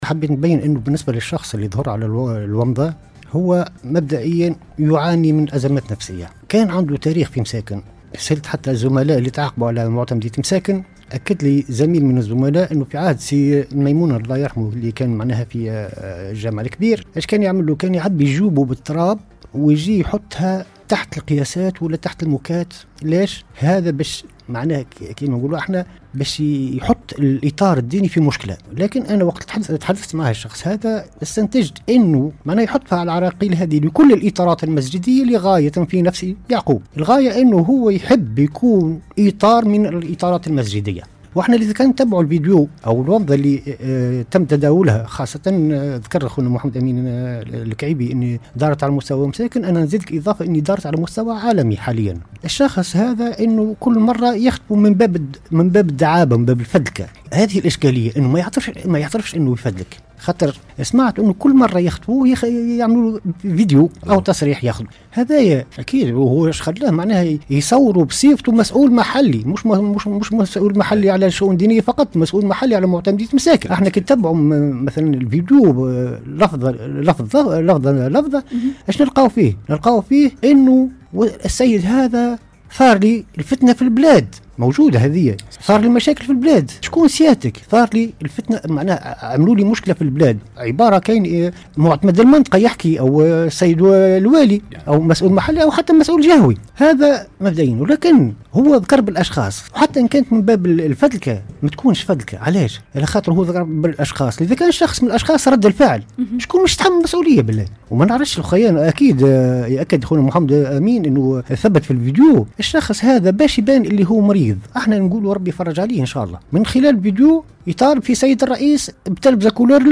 *تصريح